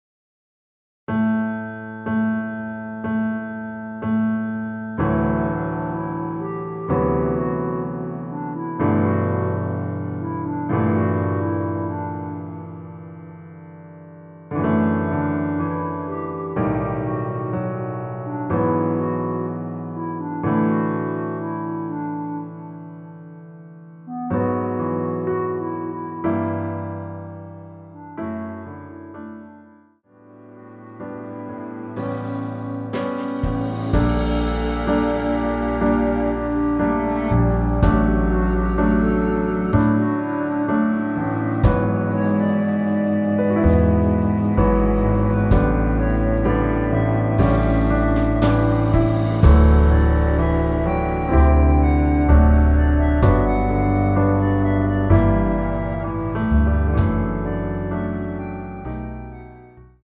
원키에서(-5)내린 멜로디 포함된 MR입니다.
앞부분30초, 뒷부분30초씩 편집해서 올려 드리고 있습니다.
중간에 음이 끈어지고 다시 나오는 이유는